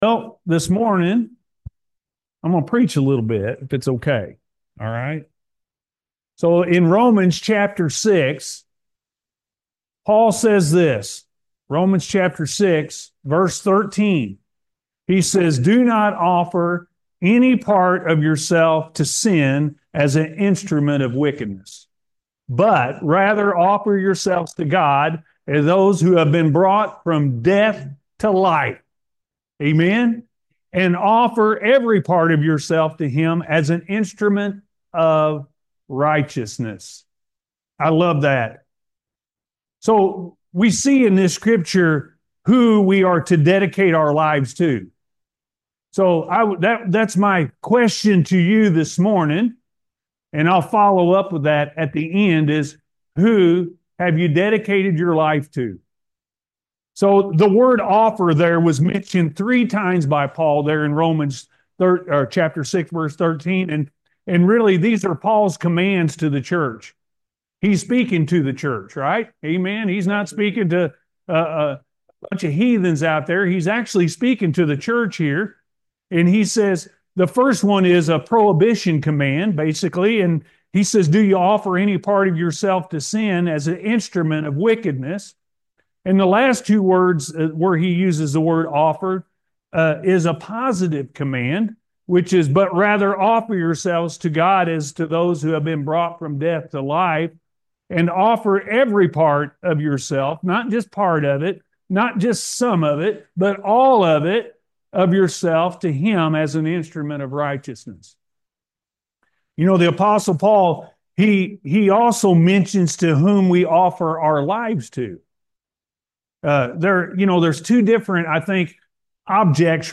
Who Have You Dedicated Your Life To?-A.M. Service – Anna First Church of the Nazarene